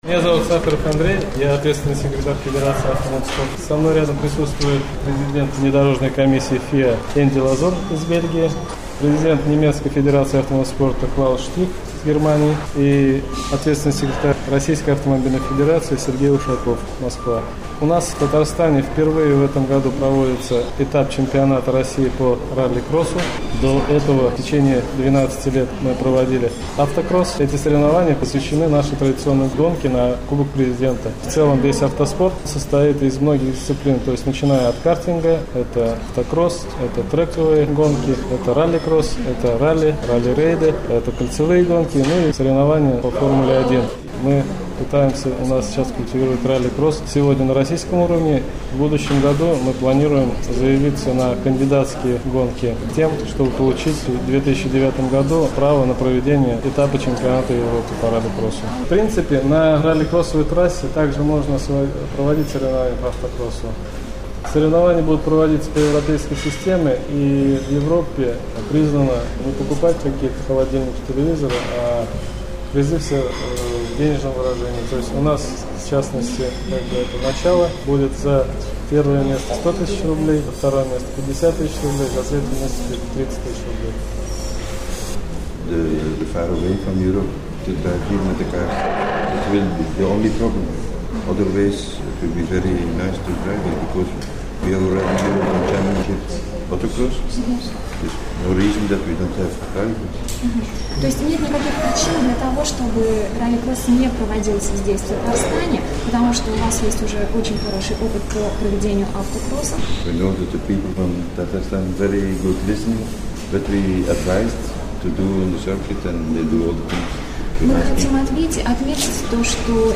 Аудиорепортаж
Пресс-конфренция, посвященная проведению автогонок на "Кубок Президента Республики Татарстан":